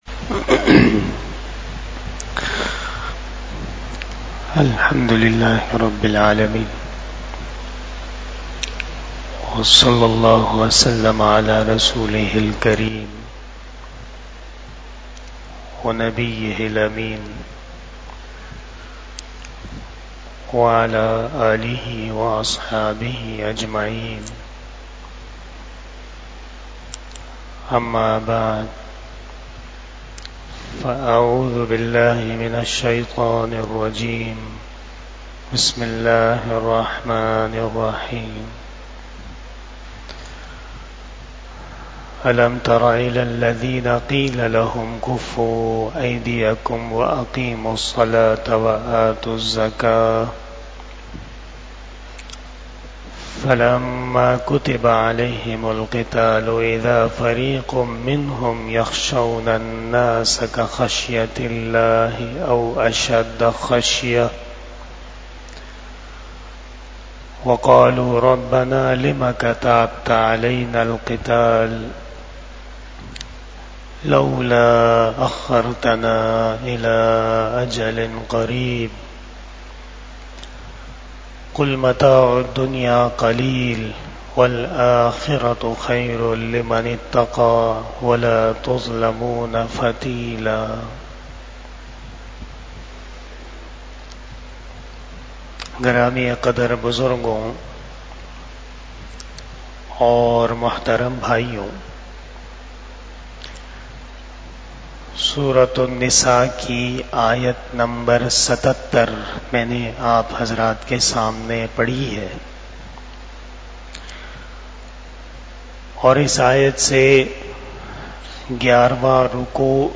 27 Shab E Jummah Bayan 15 August 2024 (10 Safar 1446 HJ)